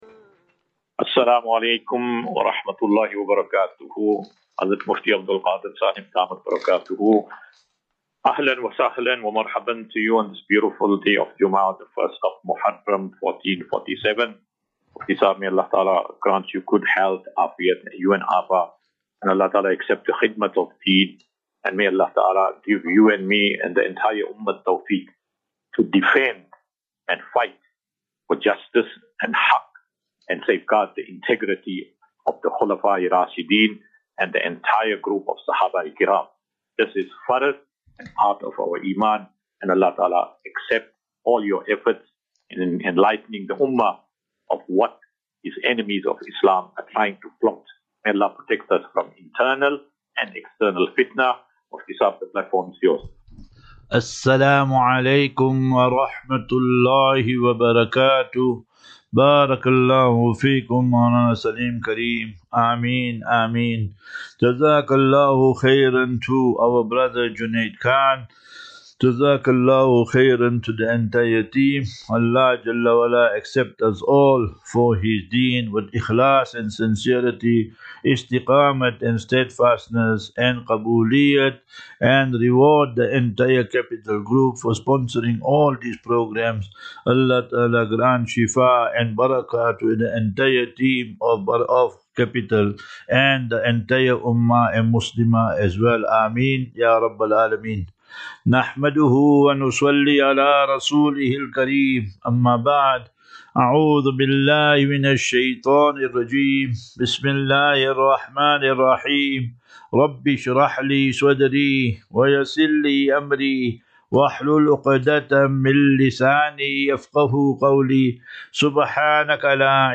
27 Jun 27 June 2025. Assafinatu - Illal - Jannah. QnA